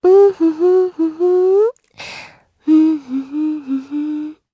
Erze-Vox_Hum_jp.wav